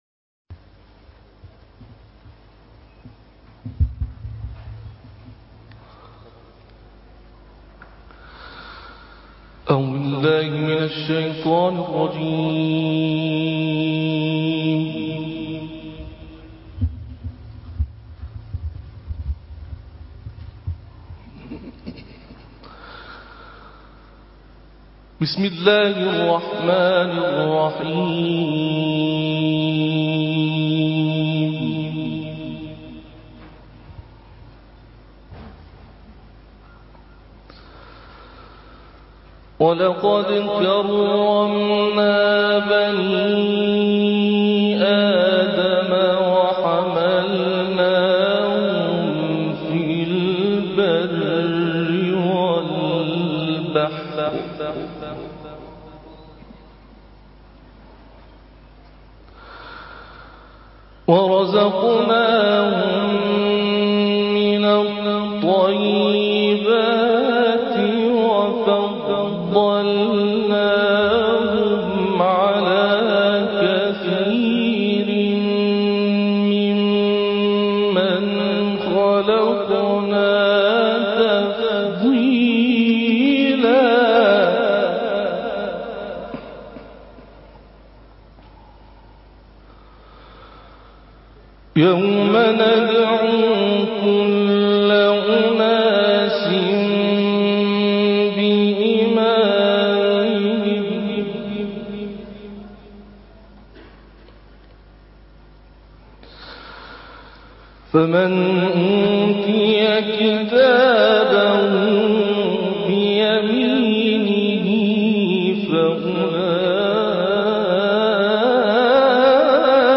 تلاوت
اقلیم کردستان عراق آیات 70 تا 84 سوره اسراء را با صوتی زیبا تلاوت کرده‌اند که تقدیم مخاطبان ایکنا می‌شود.